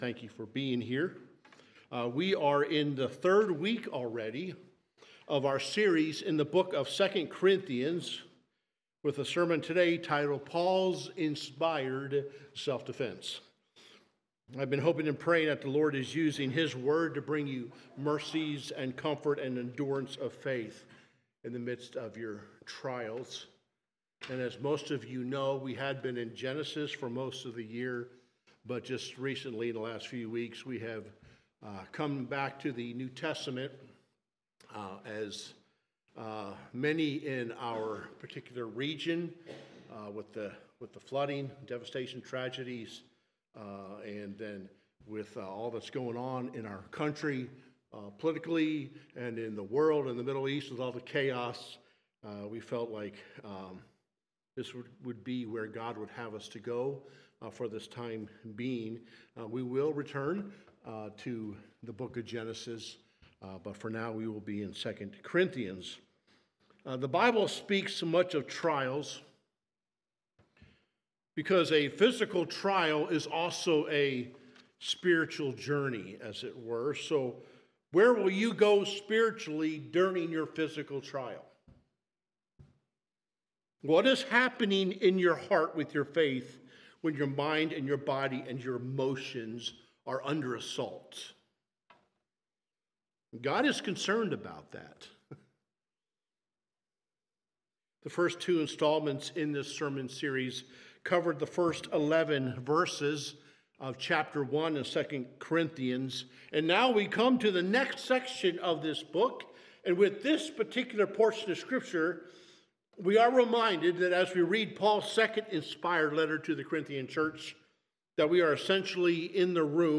Sermons | Highland Baptist Church